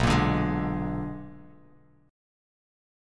BmM13 Chord
Listen to BmM13 strummed